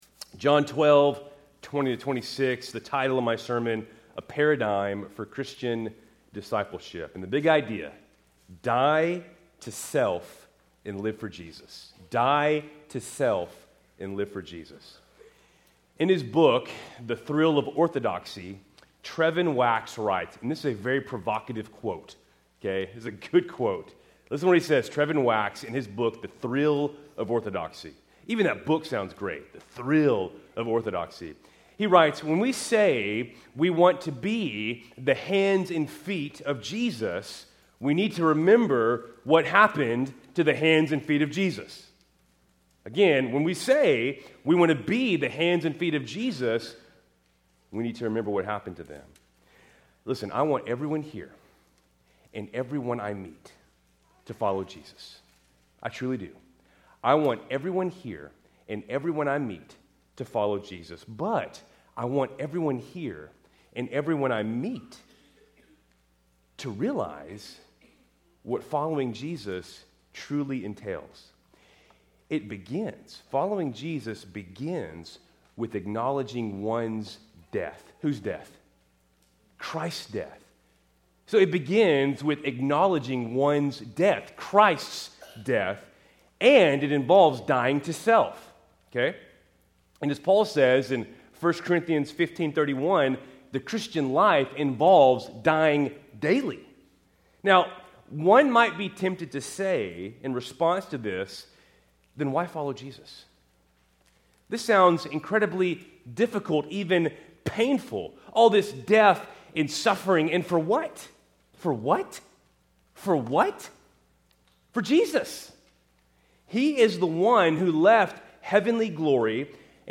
Keltys Worship Service, May 11, 2025 (Mother’s Day)